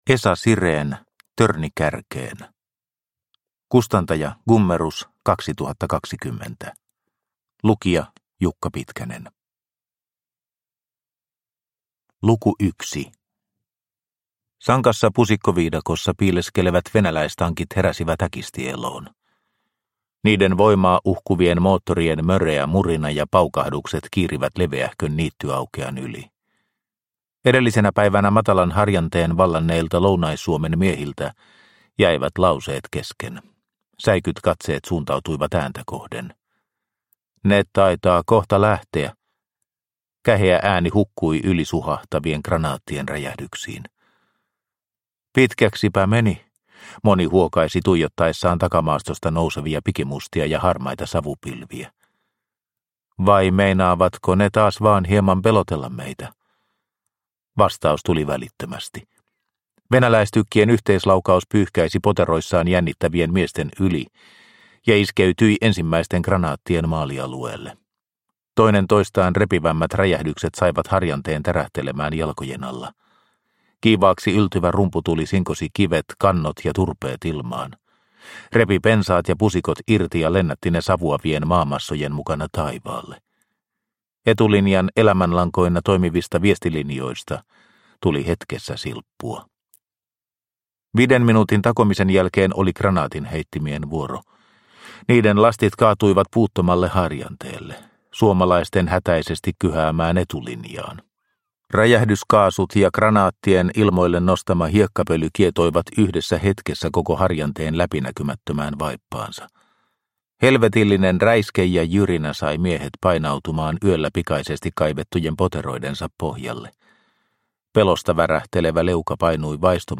Törni kärkeen – Ljudbok – Laddas ner